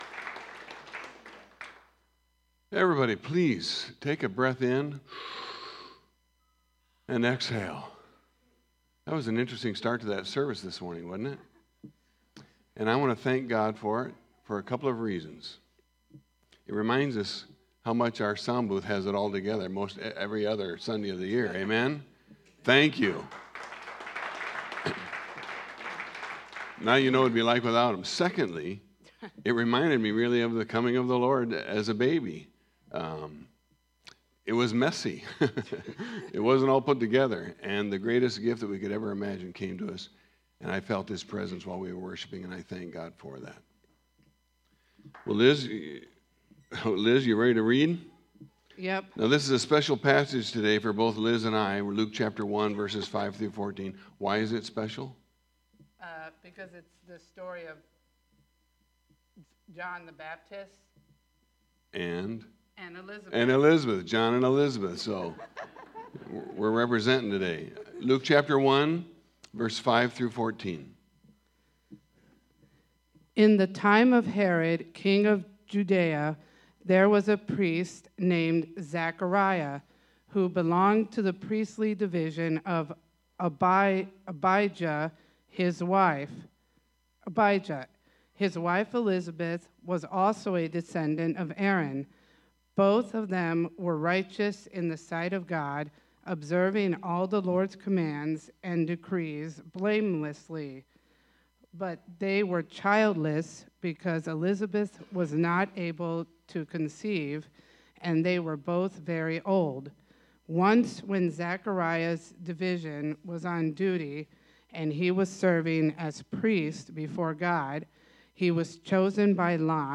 Hear recorded versions of our Sunday sermons at your leisure, in the comfort of your own personal space.
Service Type: Sunday Morning